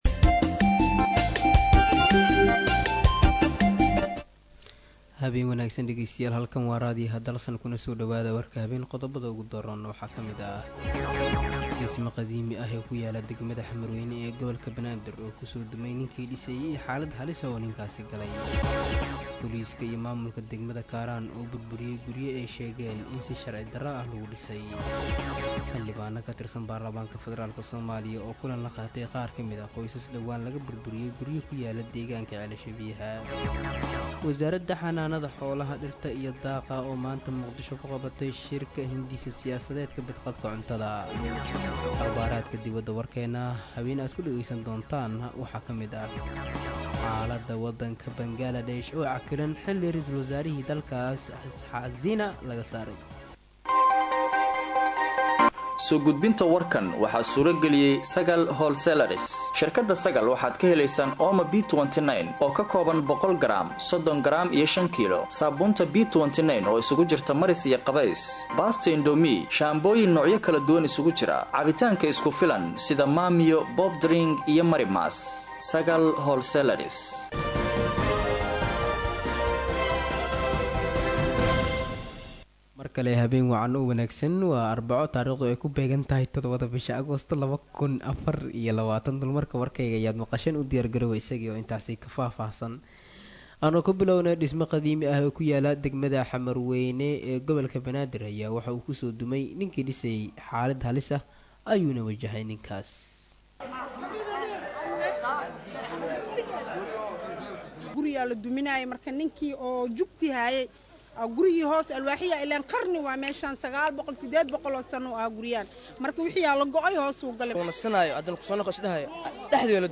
Dhageyso:- Warka Habeen Ee Radio Dalsan 07/08/2024